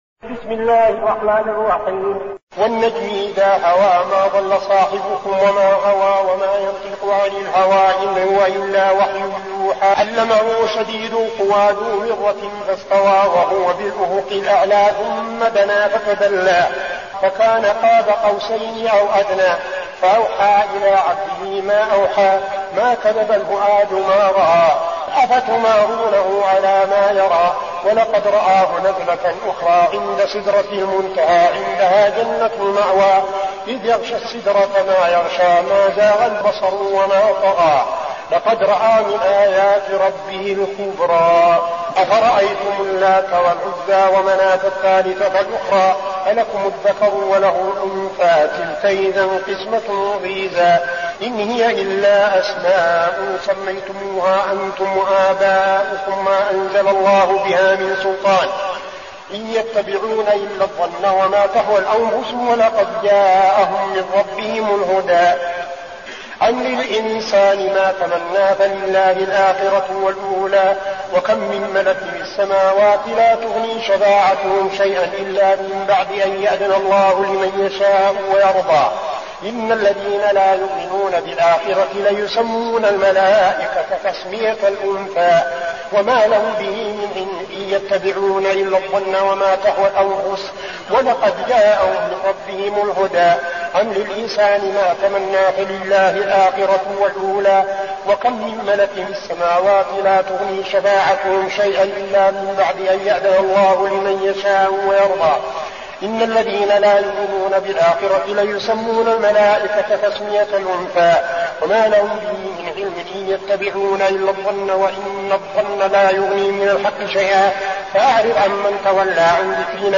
المكان: المسجد النبوي الشيخ: فضيلة الشيخ عبدالعزيز بن صالح فضيلة الشيخ عبدالعزيز بن صالح النجم